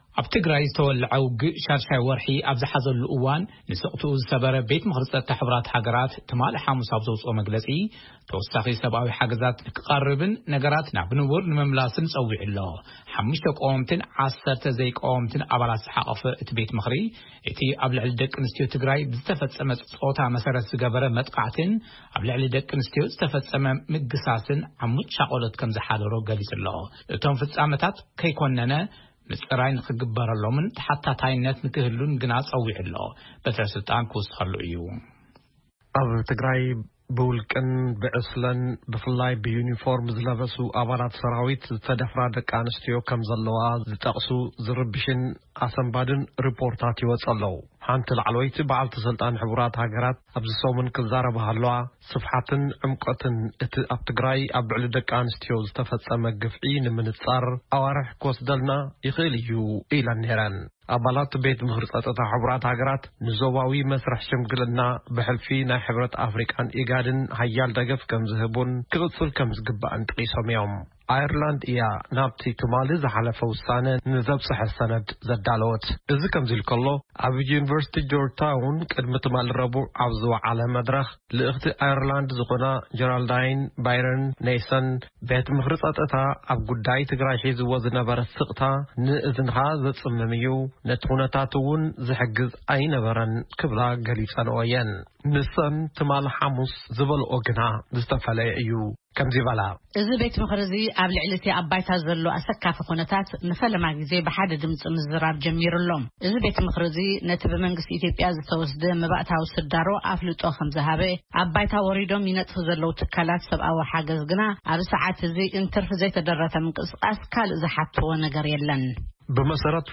ሪፖርት ካብ ሕ/ሃ